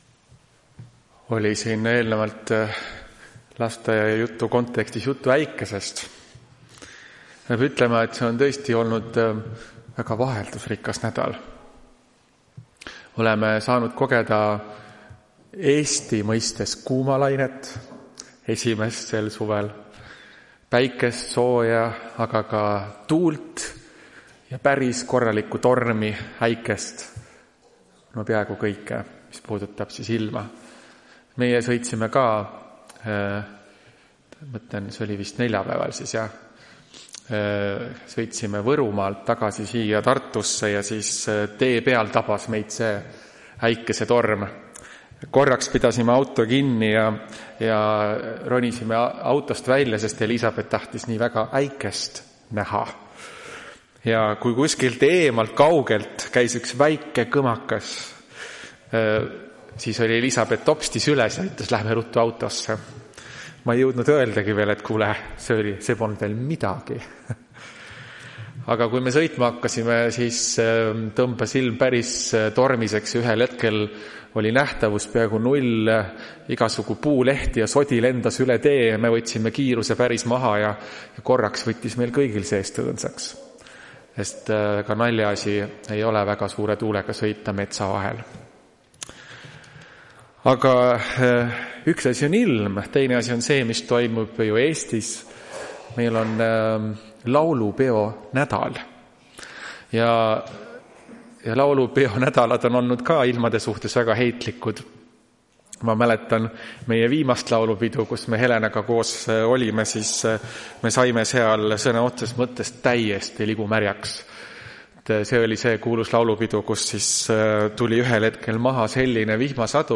Tartu adventkoguduse 05.07.2025 hommikuse teenistuse jutluse helisalvestis.
Jutlused